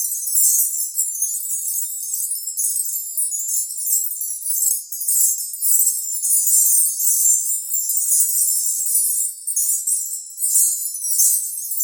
magic_sparkle_gem_loop_03.wav